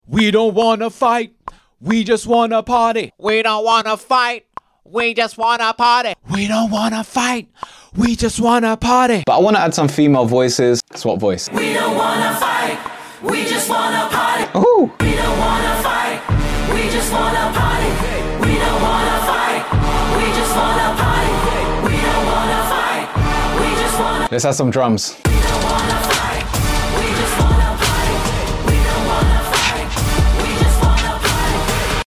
I usually Record my vocals and sound like different people, Then Change my voice to New roaylty free voices, rappers singers, female and male to give it that Huge Choir effect.